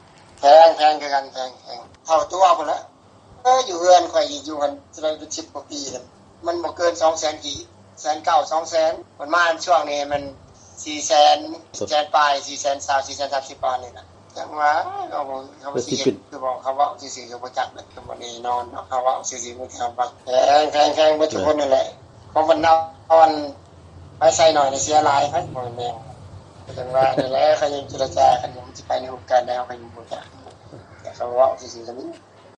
ເຊີນຟັງການສຳພາດ ຜູ້່ໃຊ້ໄຟຟ້າ ໃນ ສປປ ລາວ